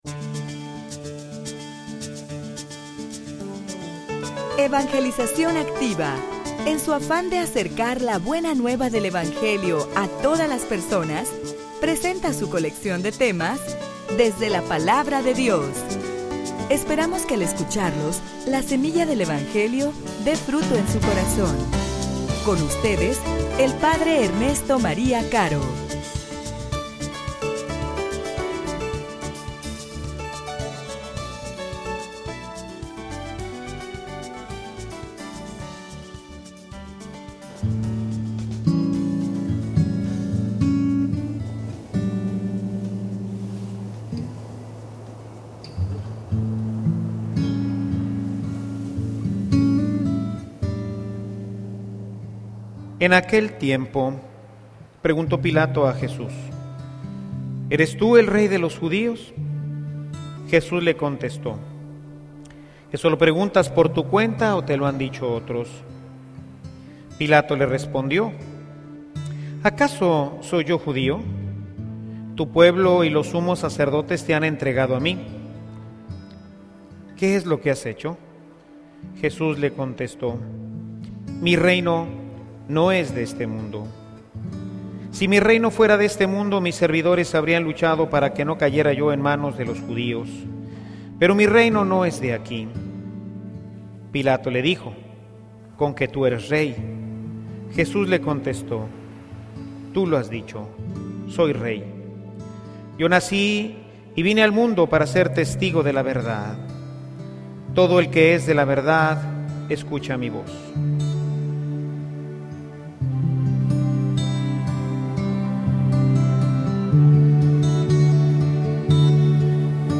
homilia_La_fe_que_nos_lleva_al_Reino.mp3